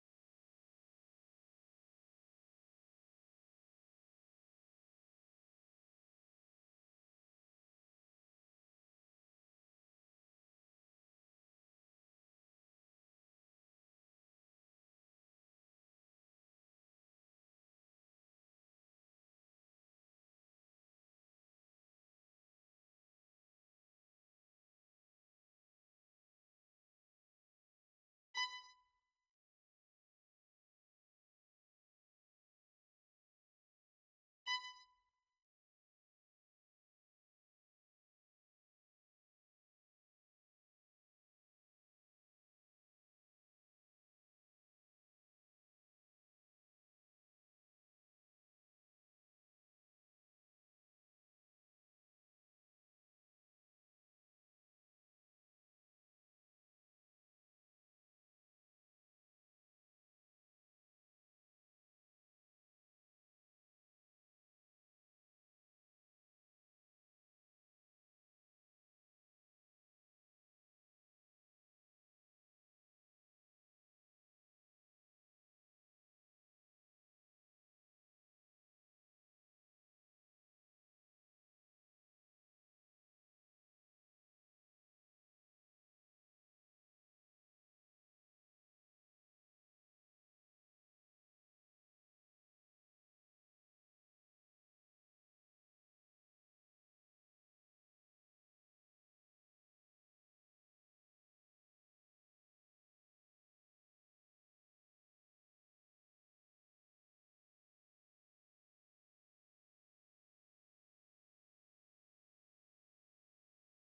04-Rondo-05-Violin_2.mp3